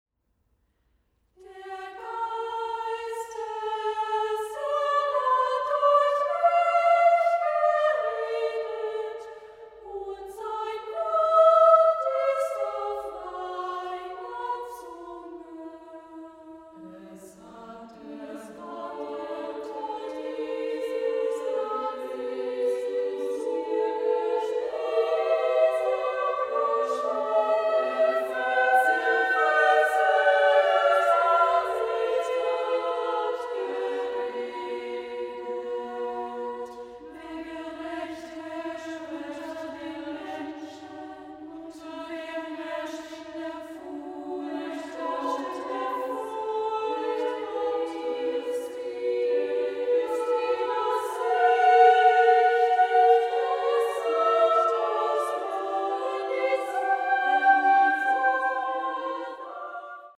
Works for Women’s and Children’s Choir and Solo Songs